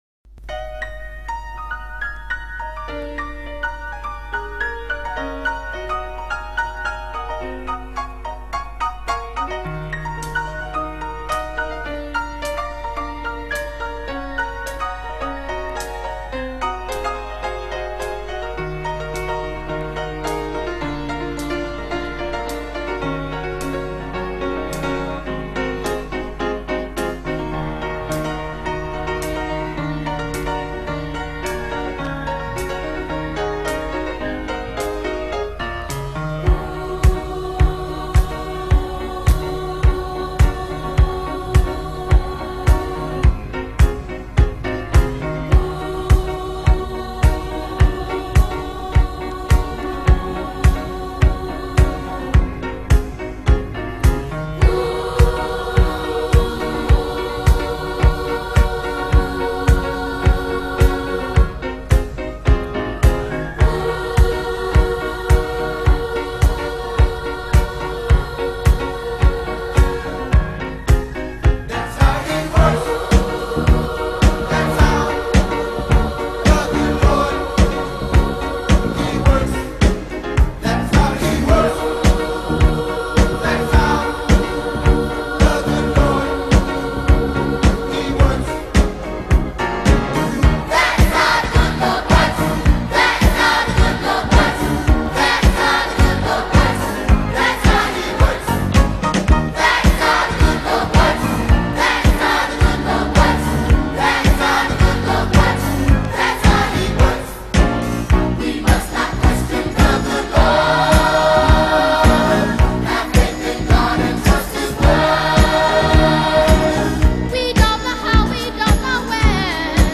is a joyous, hands in the air, gospel/house hybrid